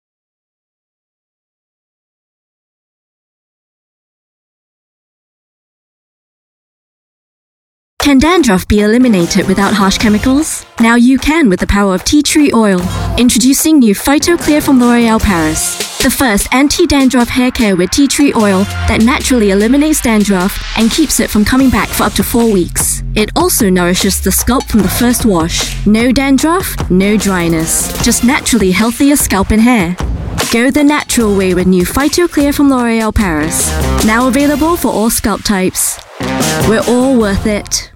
Voice Samples: L'Oreal PhytoClear
EN Asian